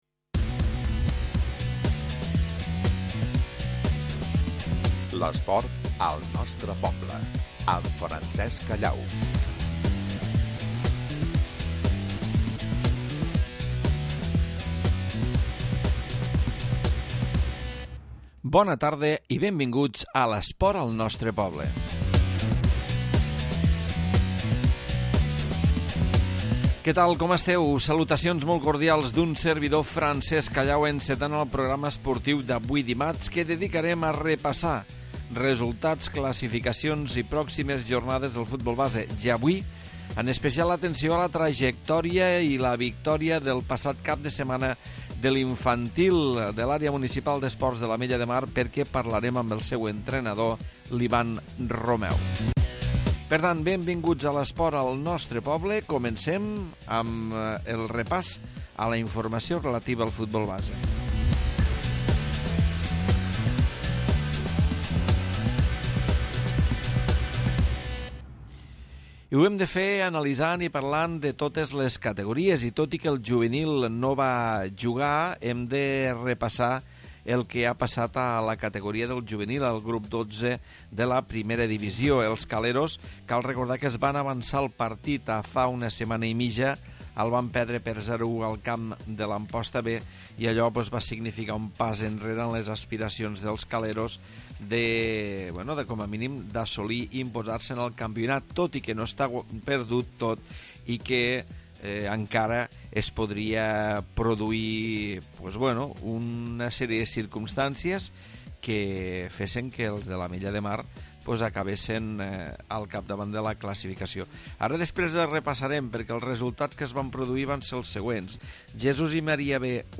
amb entrevista a l'entrenador